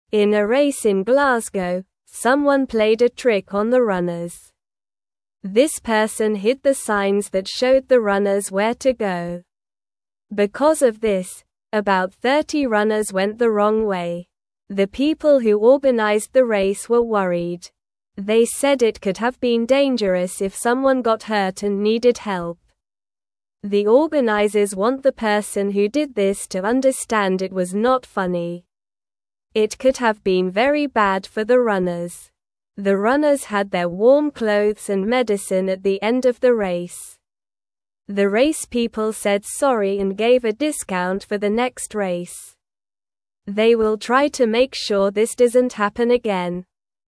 Slow
English-Newsroom-Beginner-SLOW-Reading-Trickster-Confuses-Runners-in-Glasgow-Race.mp3